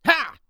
CK普通3.wav 0:00.00 0:00.50 CK普通3.wav WAV · 43 KB · 單聲道 (1ch) 下载文件 本站所有音效均采用 CC0 授权 ，可免费用于商业与个人项目，无需署名。
人声采集素材/男2刺客型/CK普通3.wav